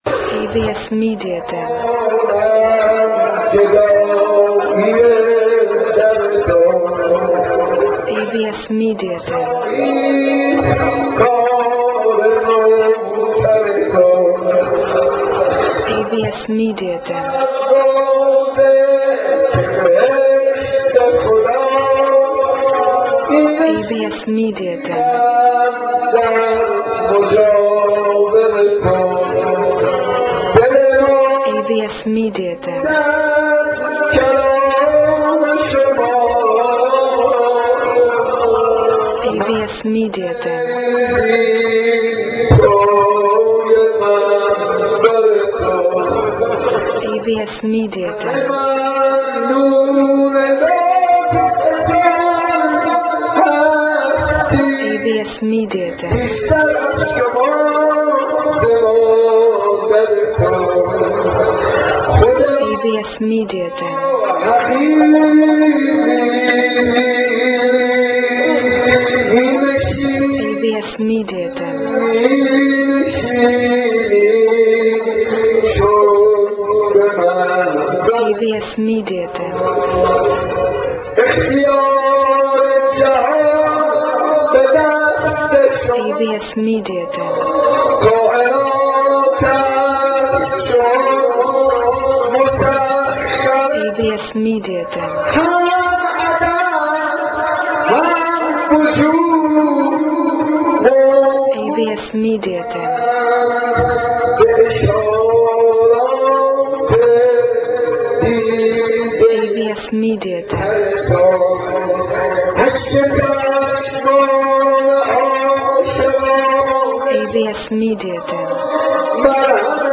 در مسجد ارک تهران برگزار شد.
غزل مرثیه ای که حاج منصور ارضی خواند: